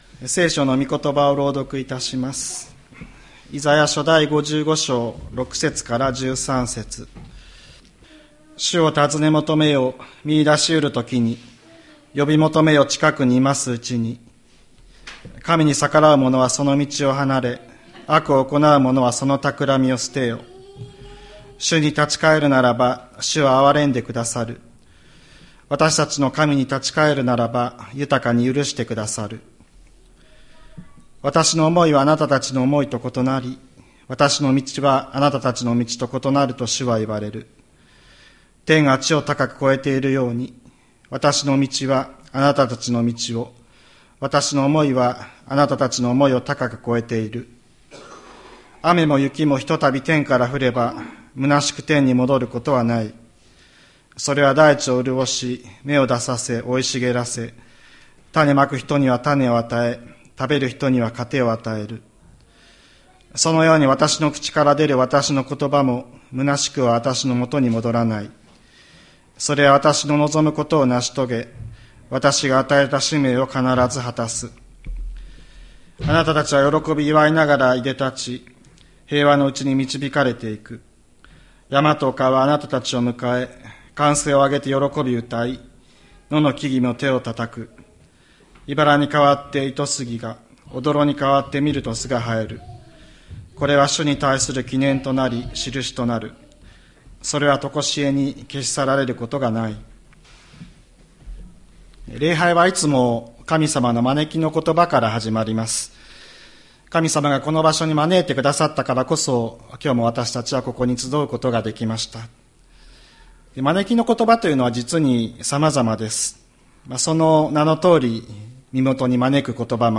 2024年11月24日朝の礼拝「喜びを生む言葉」吹田市千里山のキリスト教会
千里山教会 2024年11月24日の礼拝メッセージ。